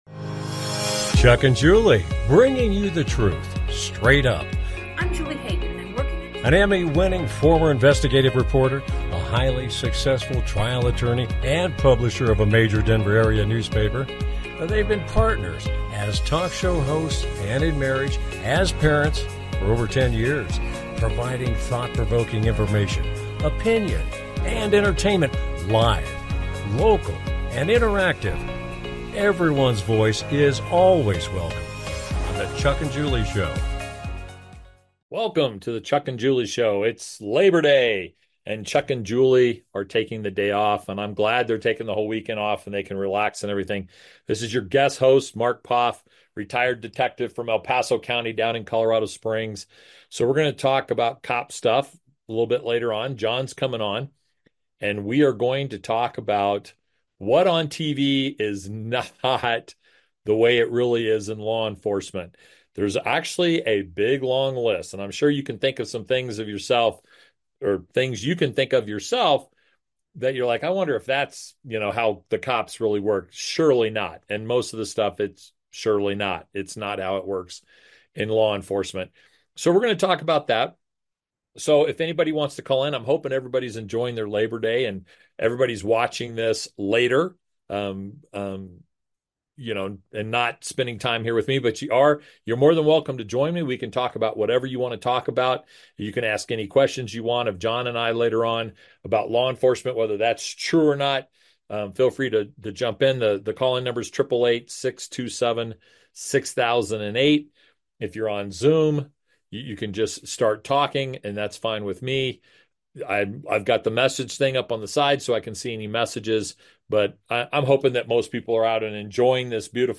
Join us for an interesting conversation between two former Detectives.